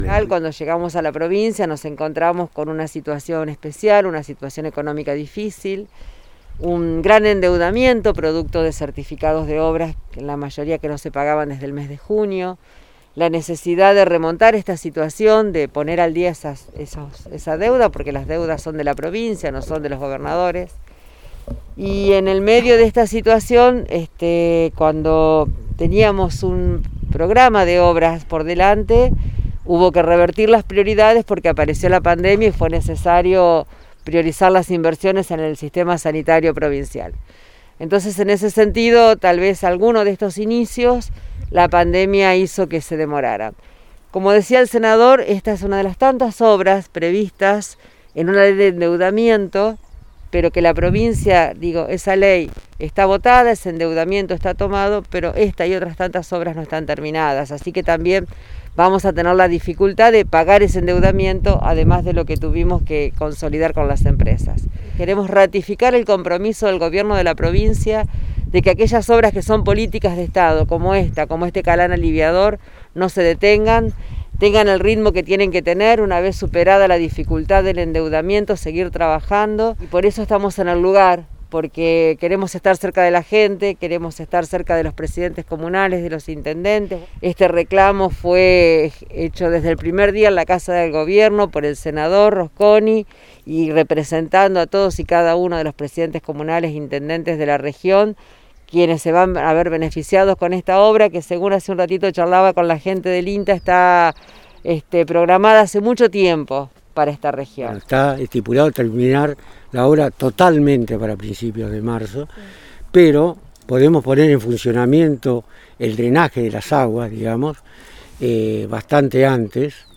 Delcaraciones de las autoridades en Arequito